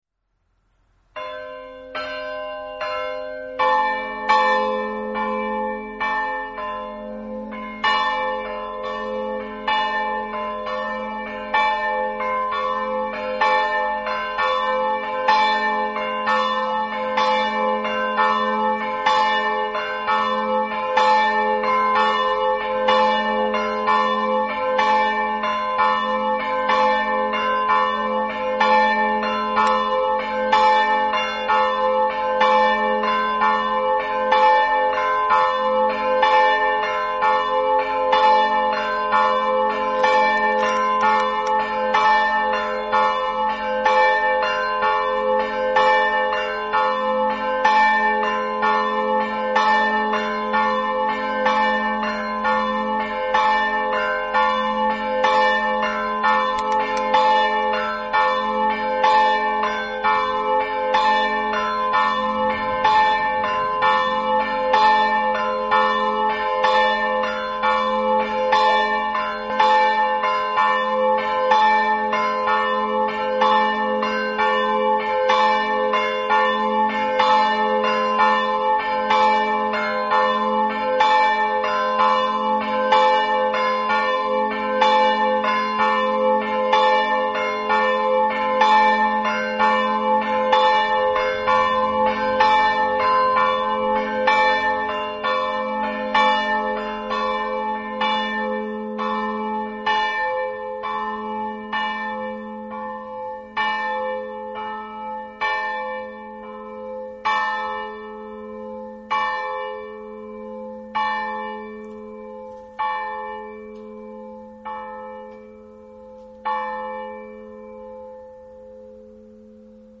Leproso di Premariacco (UD), 11 Novembre 2025
Festa Patronale di San Martino
CAMPANE